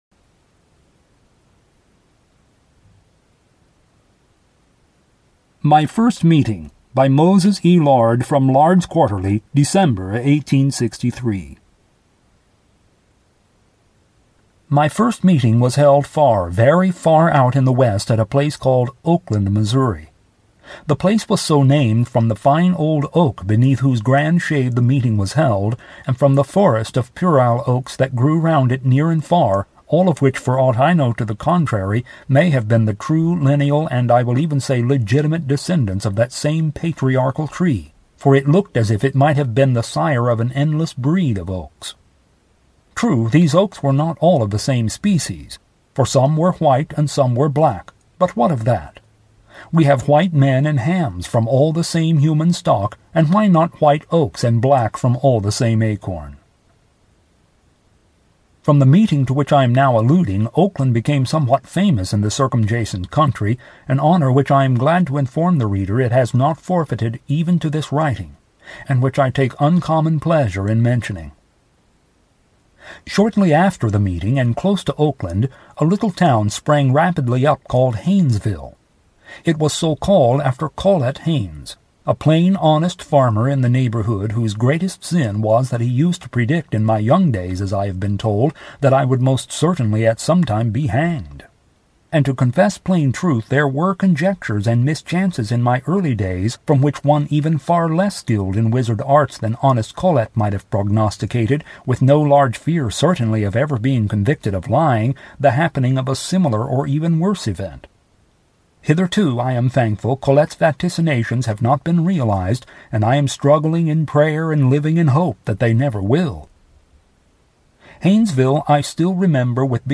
I hope my reading is worthy of its quality and hope that you enjoy listening.